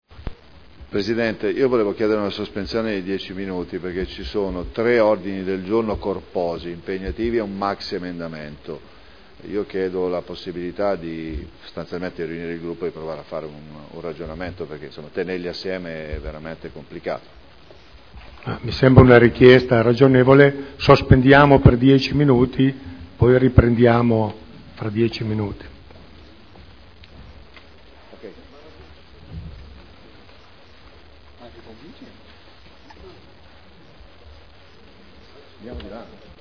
Paolo Trande — Sito Audio Consiglio Comunale
Seduta del 24/02/2011. Interviene sugli Ordini del Giorno riguardanti gli avvenimenti in Libia chiedendo una sospensione dei lavori per poter approfondire una tema così delicato.